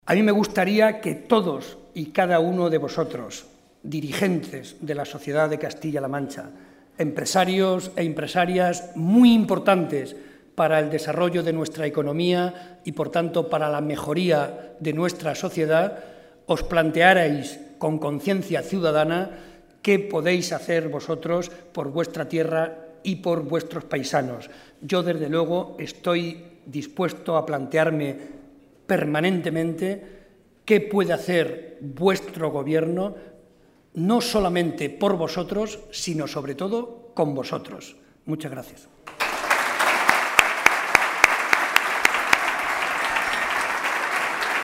José María Barreda, durante el coloquio
Cortes de audio de la rueda de prensa